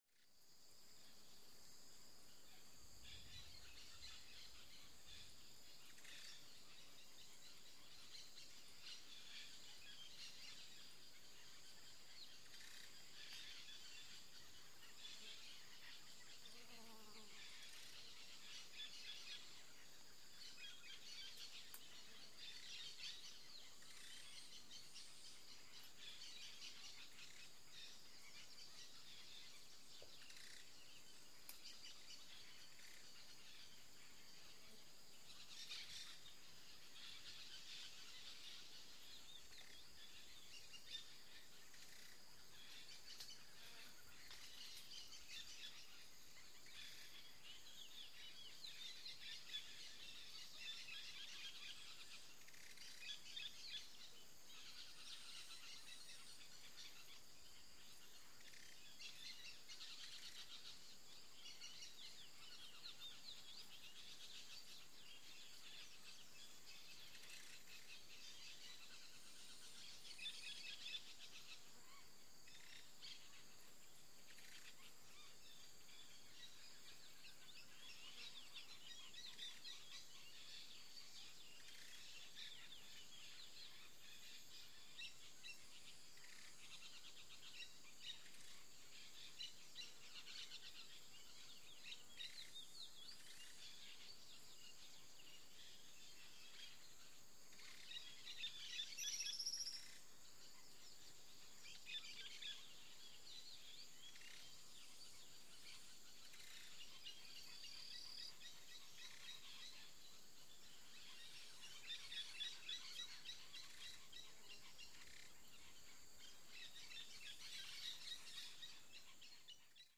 Light, Steady Bird Calls With Very Light Insect Buzz.